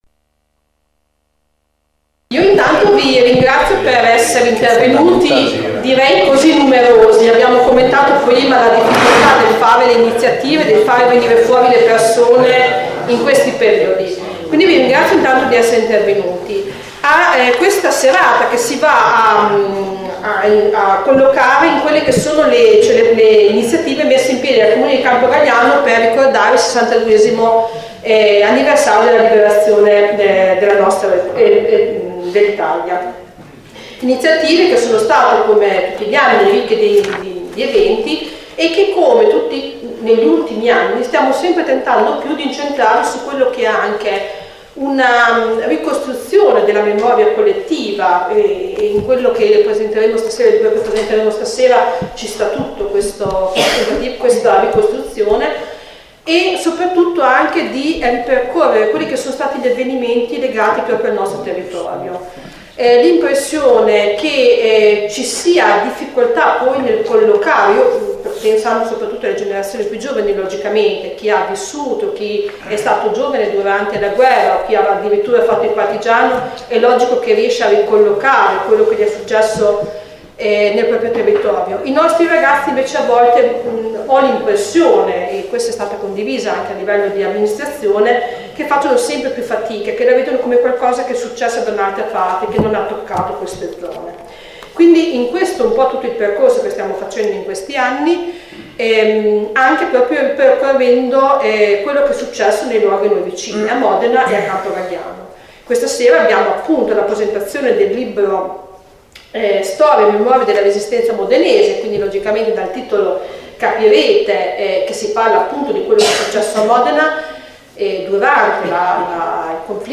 Presentazione del volume